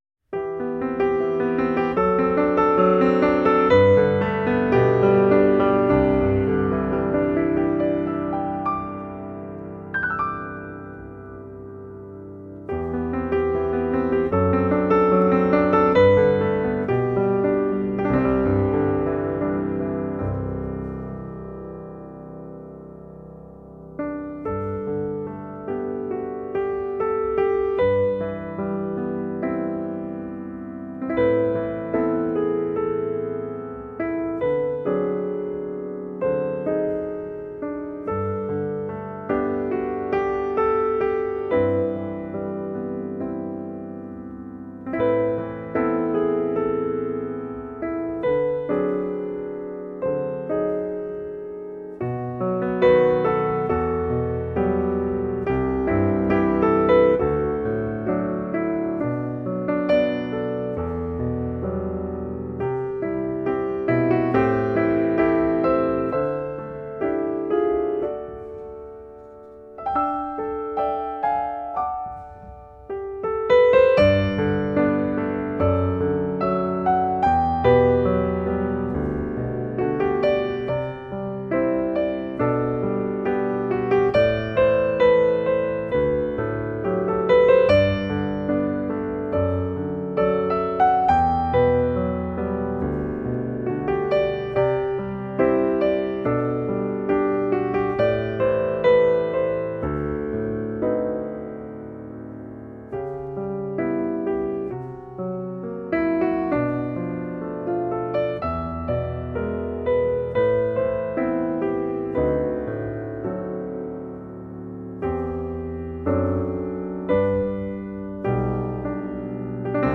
今年冬天，歌坛又多了一个好男声
加重弦乐的编制，乐曲格局更大气势更强